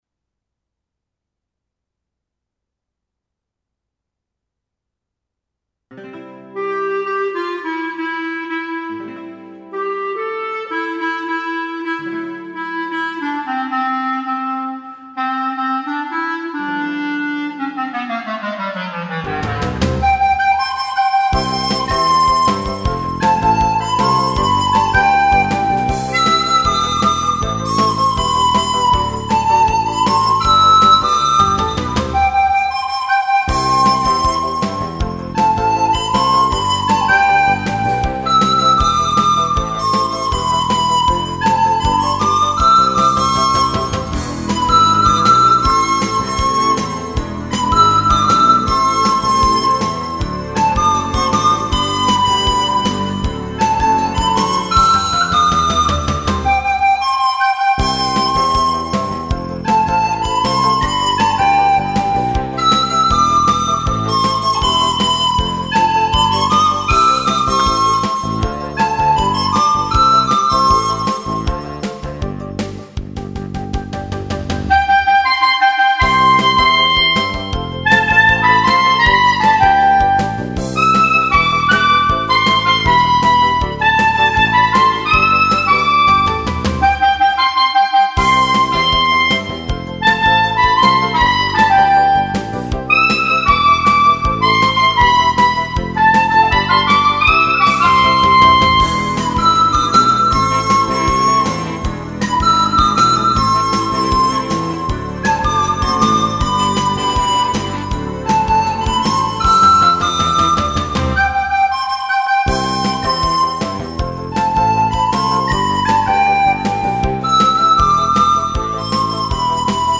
Il gruppo: "The Blue Jeans Band"
• Concerto per la festa della mamma 14/05/2006
Fatti mandare dalla mamma  al clarinetto